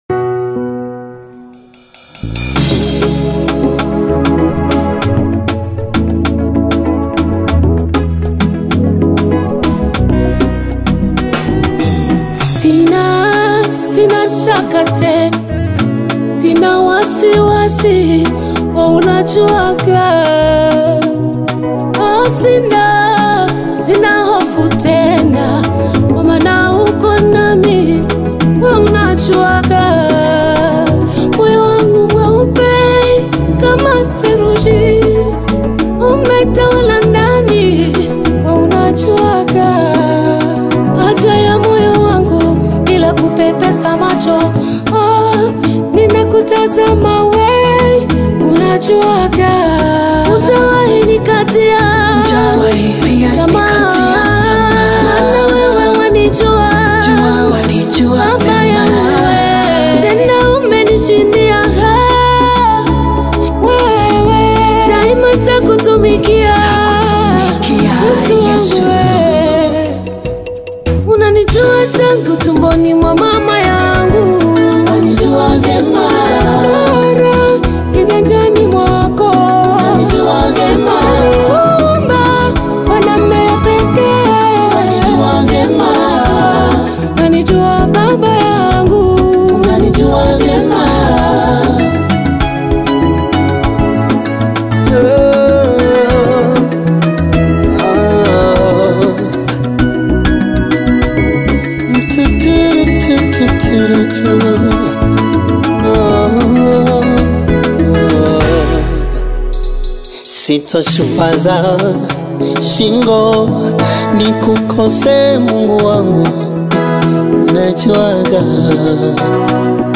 GOSPEL AUDIOS